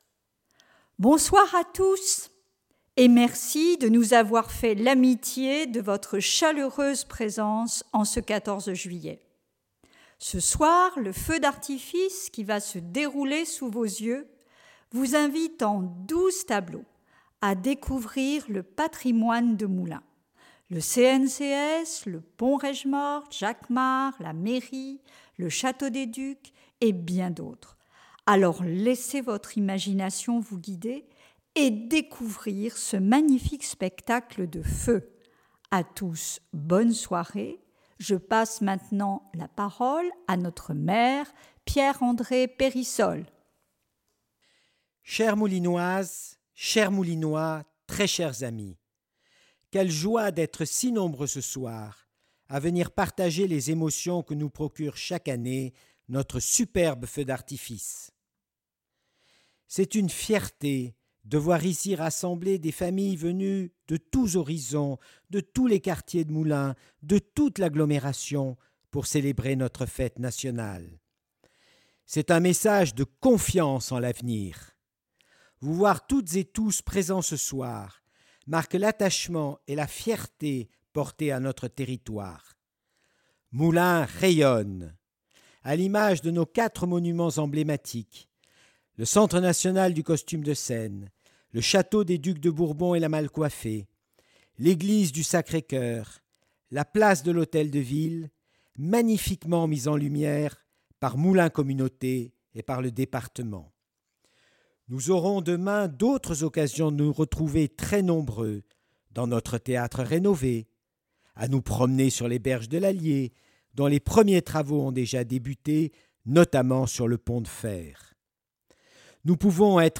Cérémonie à 16h
Discours 14 juillet 2019